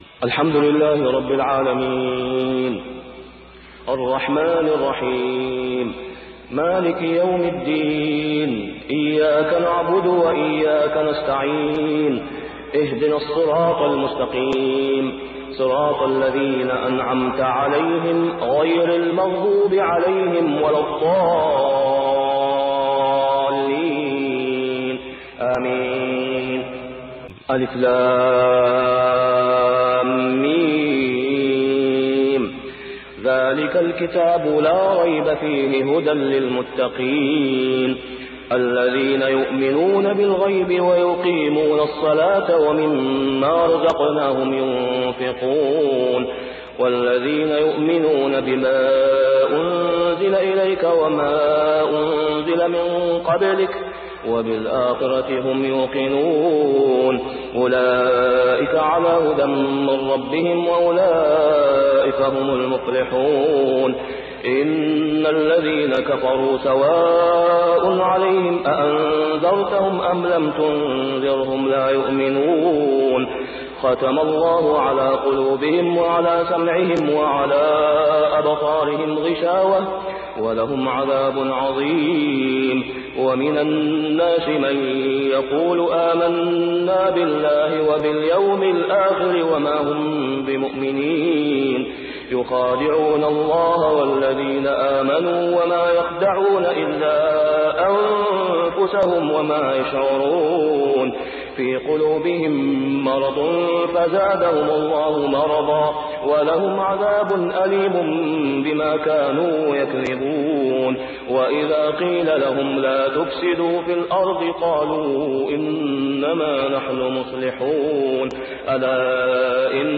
صلاة الفجر عام 1423هـ | فواتح سورة البقرة 1-39 | > 1423 🕋 > الفروض - تلاوات الحرمين